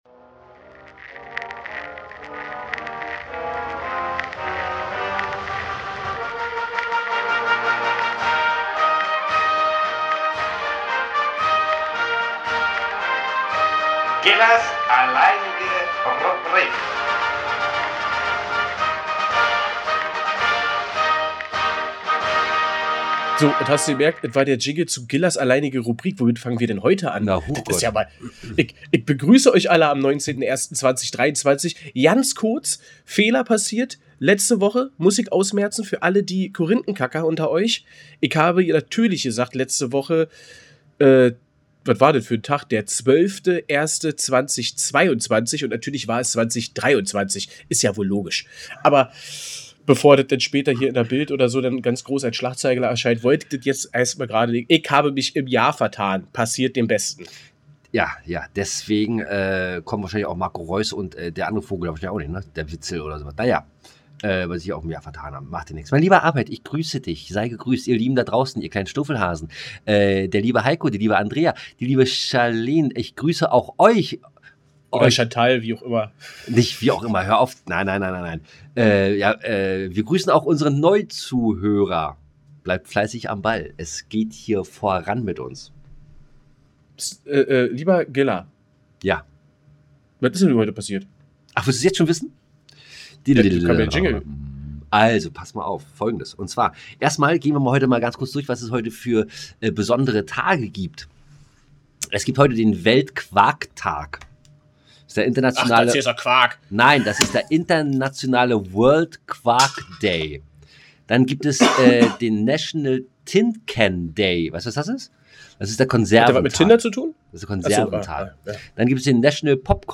Erleben Sie atemberaubende Specialeffects, wissenswertes Wissen und einen funken Magie beim Podcast von Gilla & Arbeit. Jetzt auch als Hörspiel!